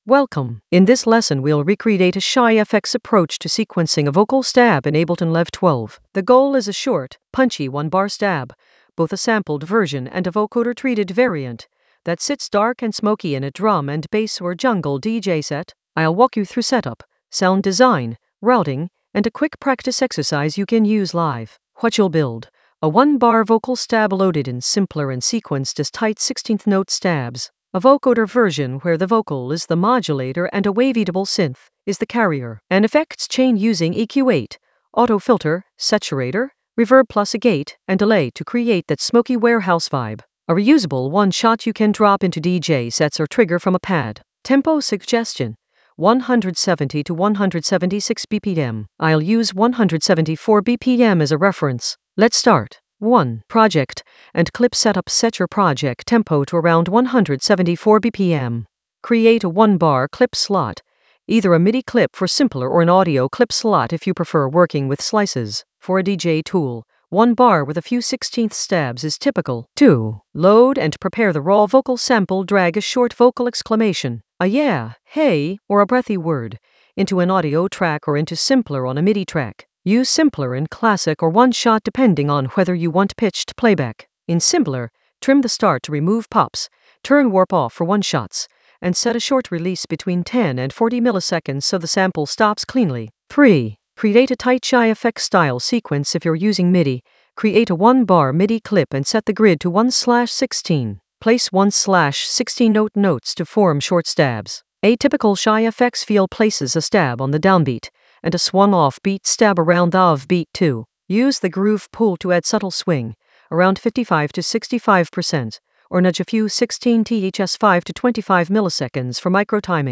An AI-generated beginner Ableton lesson focused on Shy FX approach: sequence a vocal stab in Ableton Live 12 for smoky warehouse vibes in the DJ Tools area of drum and bass production.
Narrated lesson audio
The voice track includes the tutorial plus extra teacher commentary.